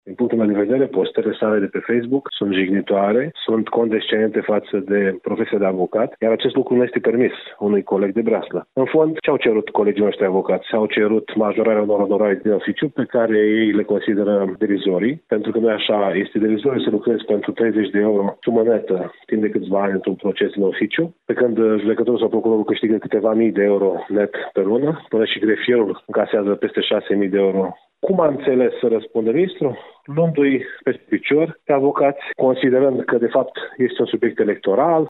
Într-o declaraţie pentru Radio Iaşi